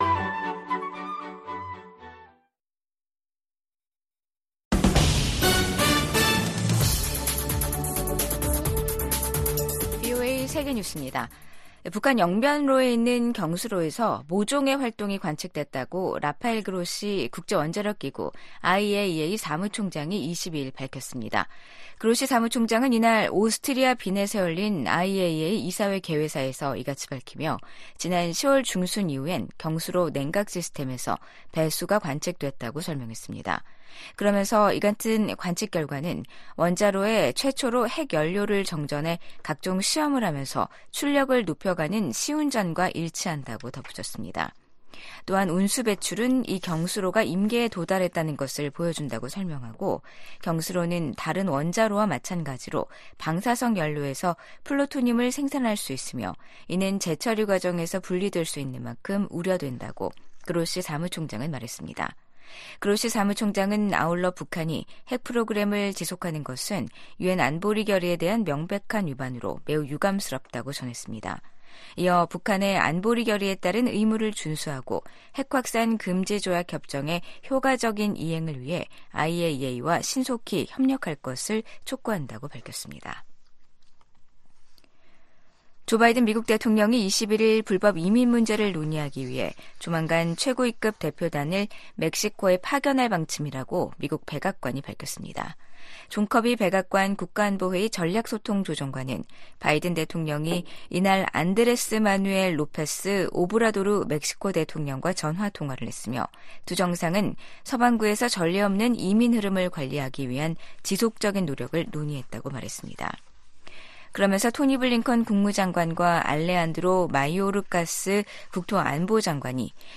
VOA 한국어 간판 뉴스 프로그램 '뉴스 투데이', 2023년 12월 22 일 2부 방송입니다. 미국이 탄도미사일 개발과 발사가 방위권 행사라는 북한의 주장을 '선전이자 핑계일 뿐'이라고 일축했습니다. 미 국방부는 북한 수뇌부를 제거하는 '참수작전'이 거론되자 북한에 대해 적대적 의도가 없다는 기존 입장을 되풀이했습니다. 이스라엘 정부가 북한의 탄도미사일 발사를 '테러 행위'로 규정했습니다.